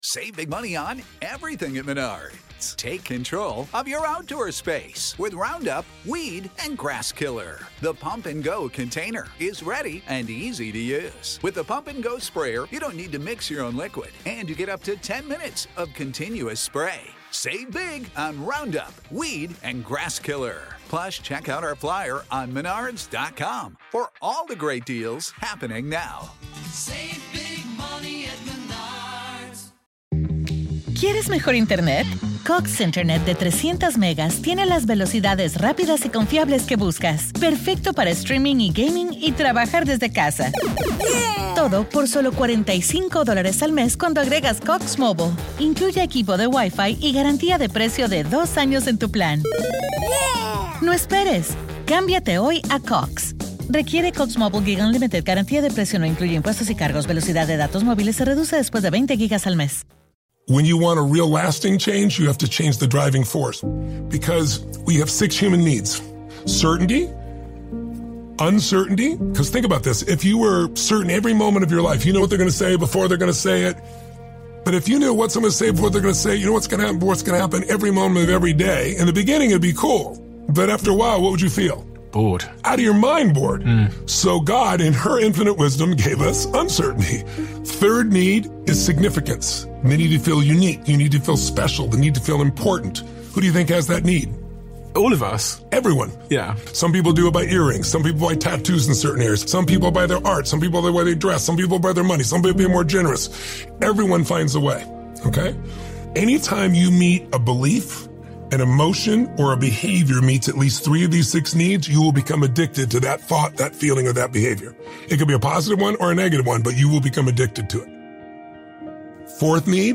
One of the Best Motivational Speeches Ever Featuring Tony Robbins.